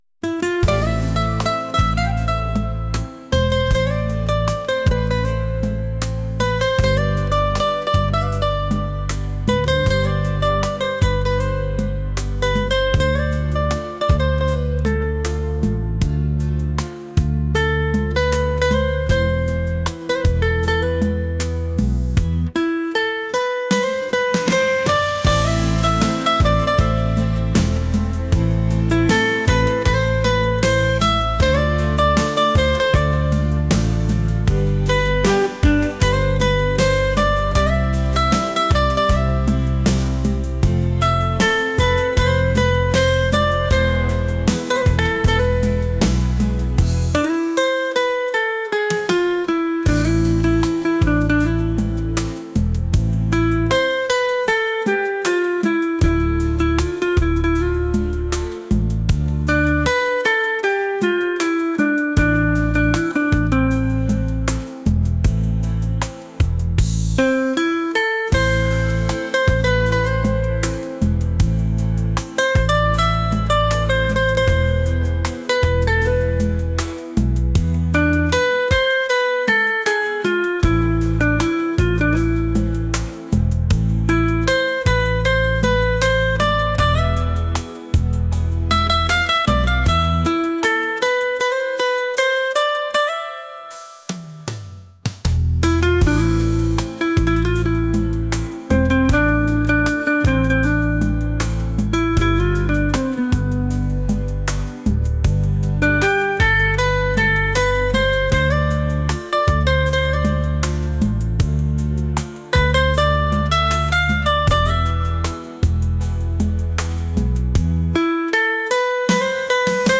world | pop | romantic